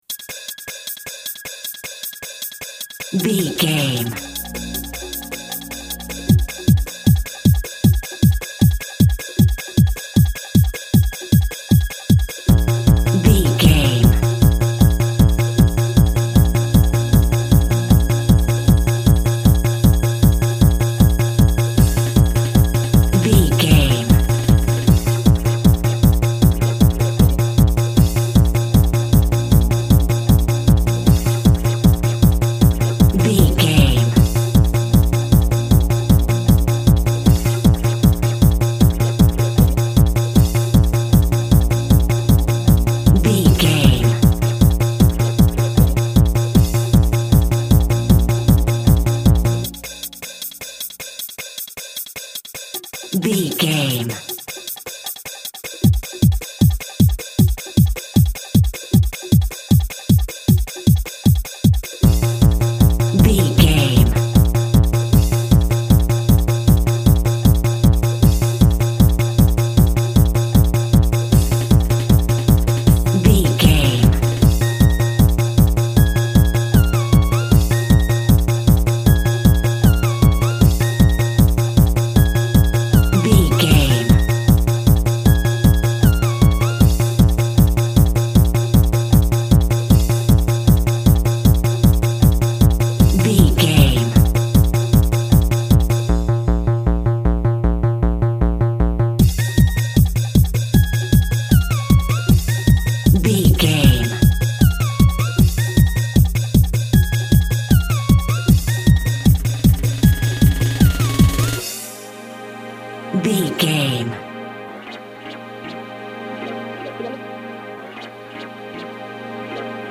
Video Game Trance Music.
Aeolian/Minor
B♭
Fast
energetic
futuristic
hypnotic
dark
drum machine
techno
synth lead
synth bass
Synth pads